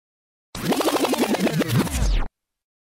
DJ Record Rewind Sound Effect
Category: Sound FX   Right: Personal